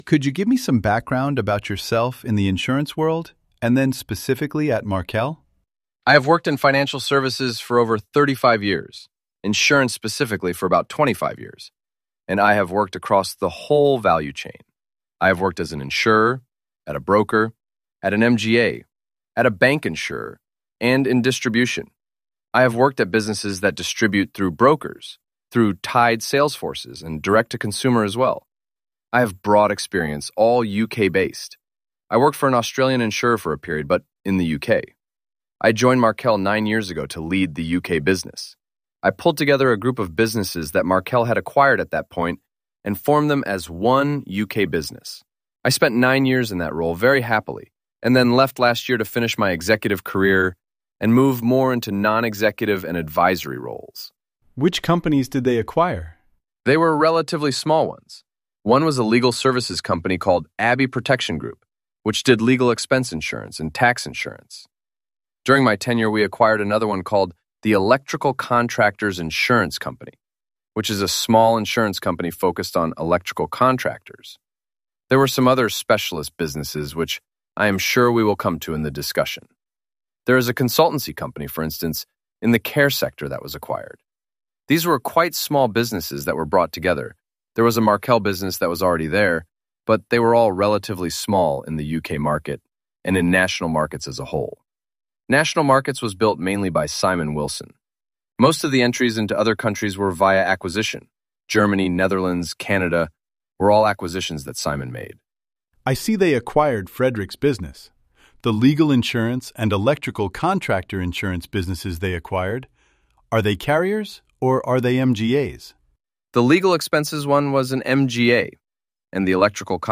In Practise Interviews